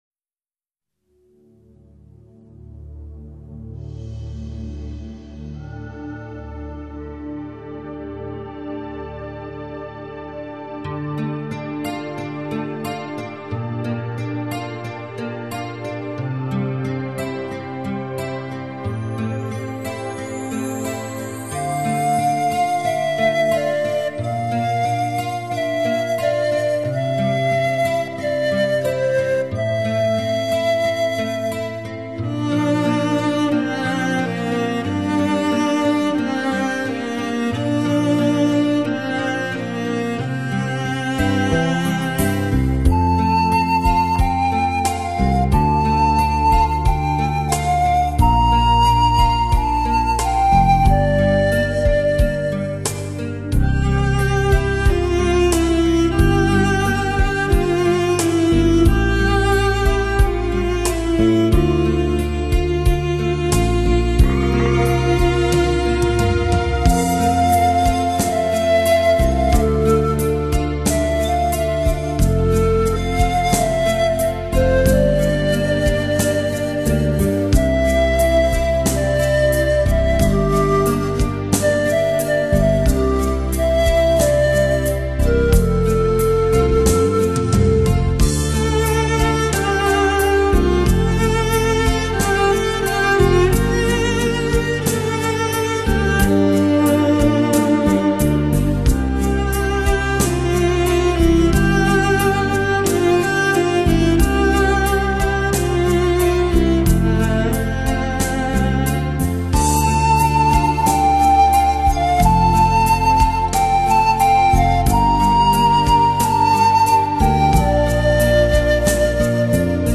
陶笛与大提琴的动心组合，自由浪漫、热情奔腾的音律随想，这是一张令你无法抗拒的冠军演奏专辑.
大提琴 长笛 口哨三重奏的抒情浪漫曲
小夜曲形式的浪漫陶笛经典金曲，第三首乐曲的另一种配器